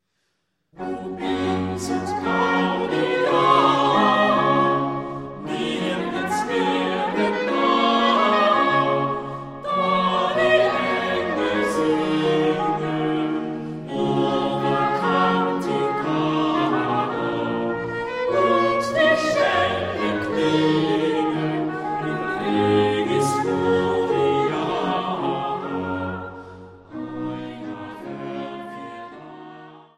Leitung und Posaune